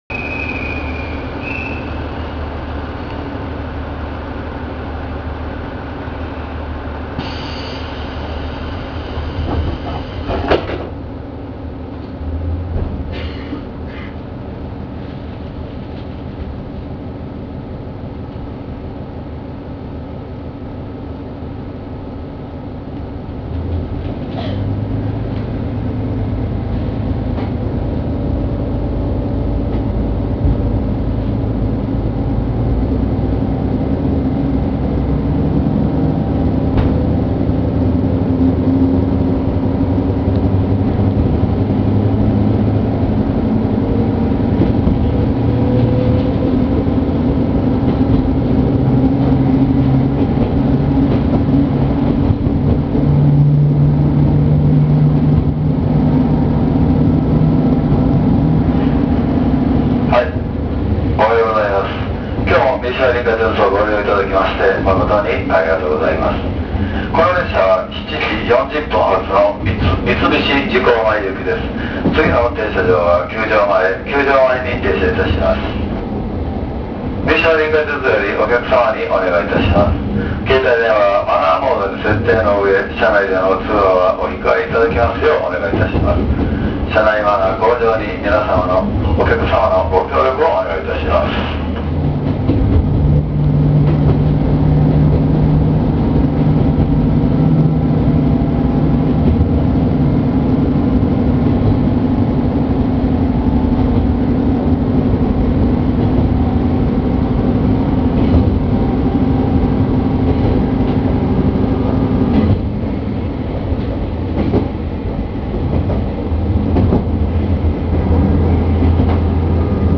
・キハ37形走行音
塗装を除き、久留里線時代と何も変化していないので走行音もそのままです。加減速は鈍め。